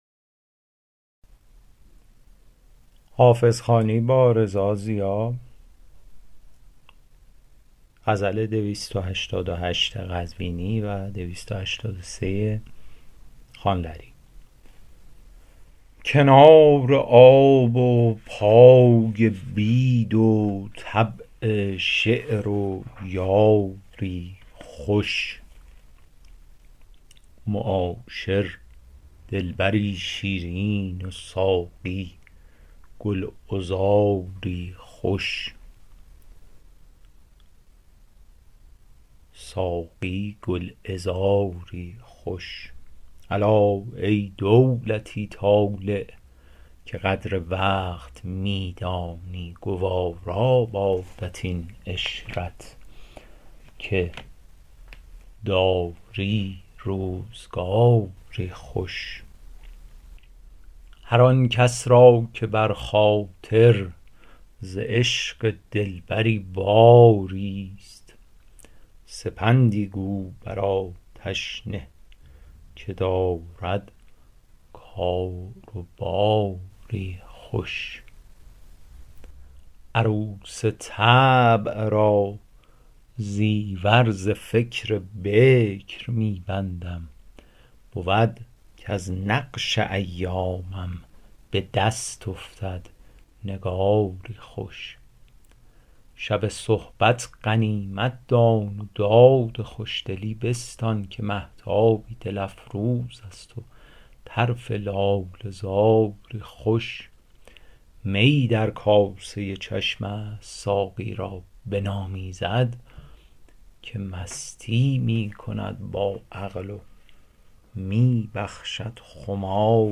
شرح صوتی غزل شمارهٔ ۲۸۸